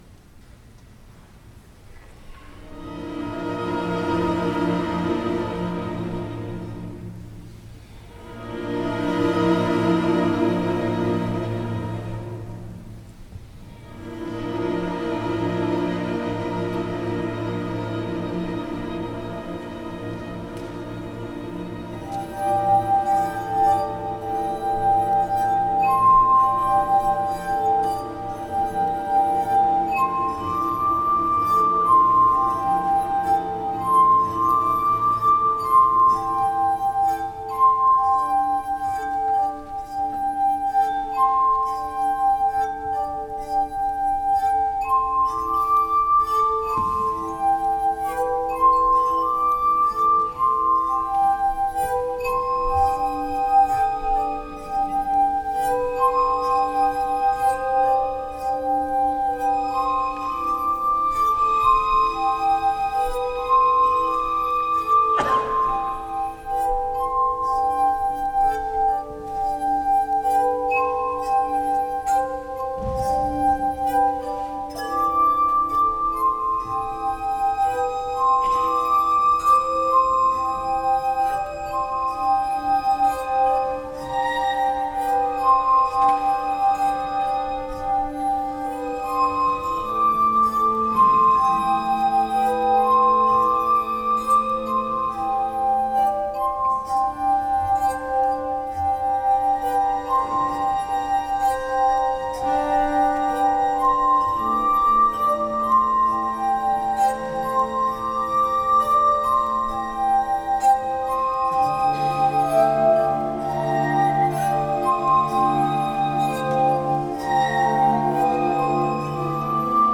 voor kamerorkest en glasorgel